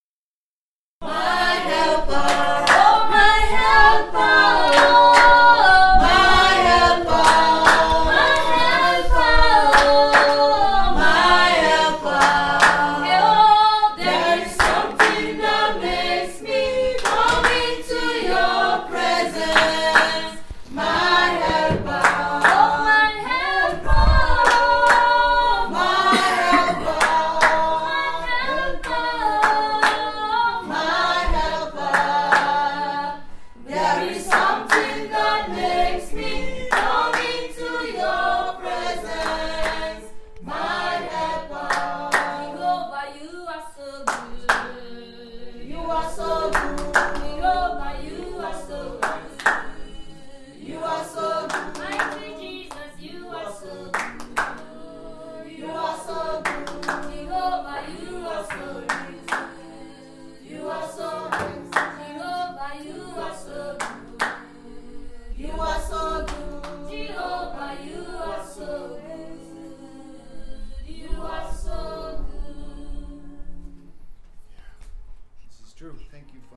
Here are five songs performed by the staff members of the ECWA Information and Computer Sciences Institute.  Some were recorded during their morning devotions, while others were part of the school’s 20th anniversary celebrations.
eicsi-sings-my-helper-asma_01-02.mp3